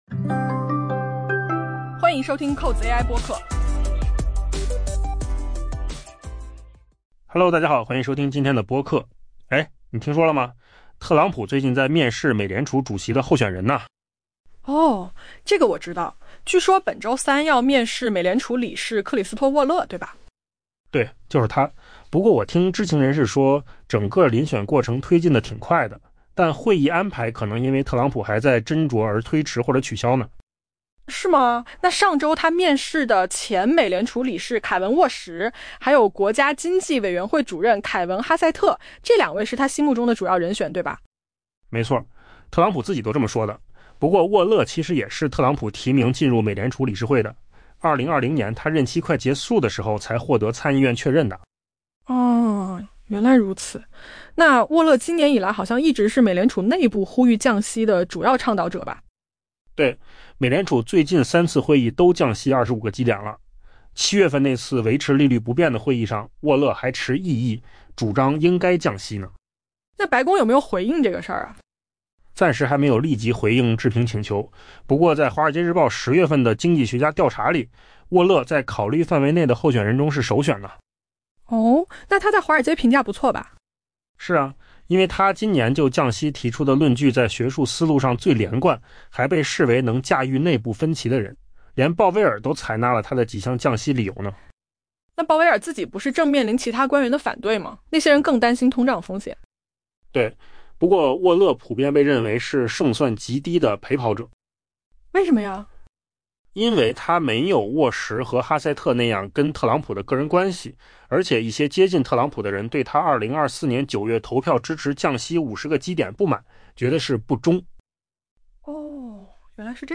AI 播客：换个方式听新闻 下载 mp3 音频由扣子空间生成 据知情人士透露，美国总统特朗普定于当地时间本周三面试又一位美联储主席的候选人——美联储理事克里斯托弗·沃勒 （Christopher Waller）。